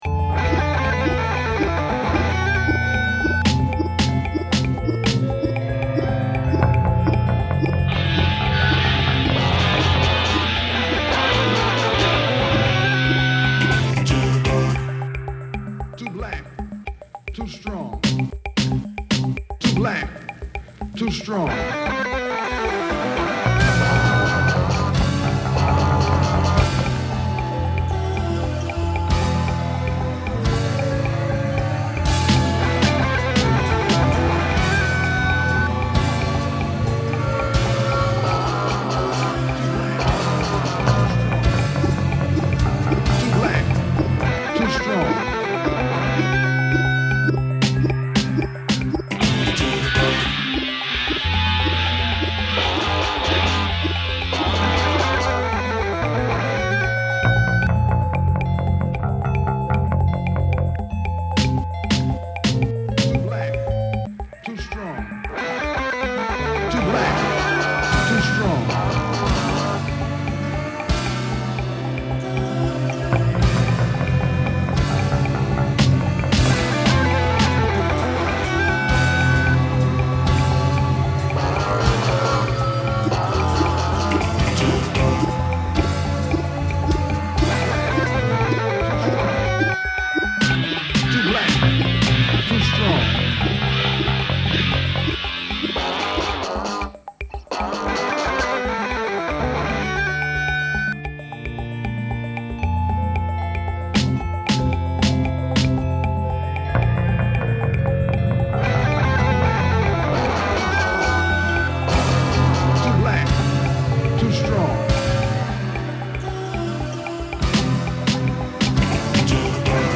quirky brief wig-out guitar
four hard beats in a row, on the bass and the snare
whiney four note theme, repeated